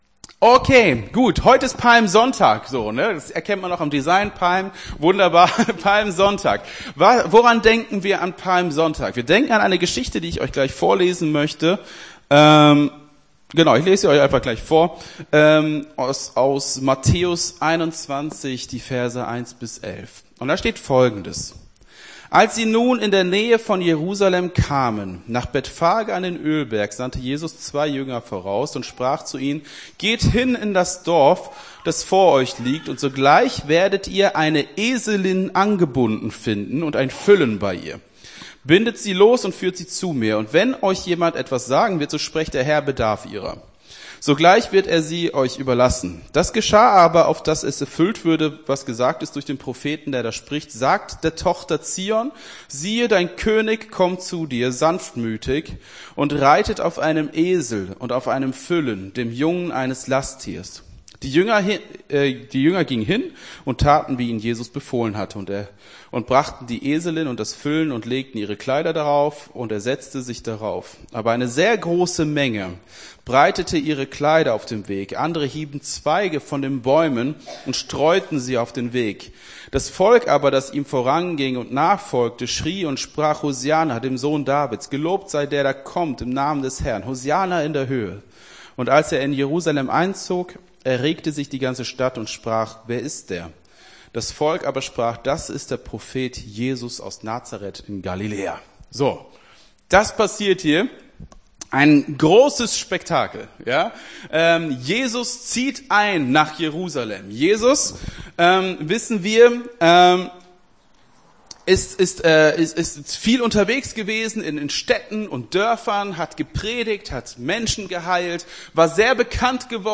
Gottesdienst 02.04.23 - FCG Hagen